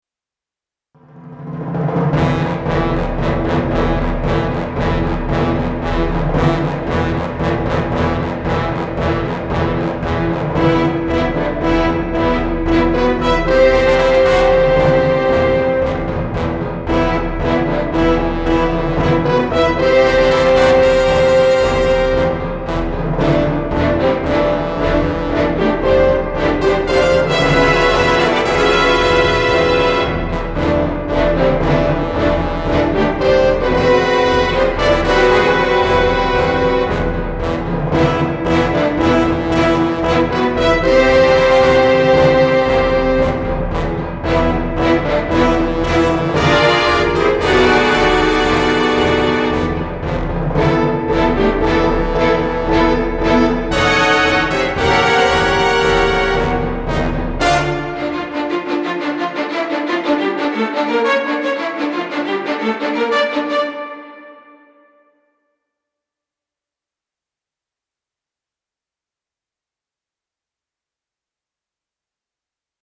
I'm working on getting the full orchestral brass to sound as good as possible using the Sample Modeling instruments, and so I thought Prokofiev's "The Enemy God and the Danse of the Black Spirits" from his Scythian Suite would be a good bit of music to use.
Sounds great!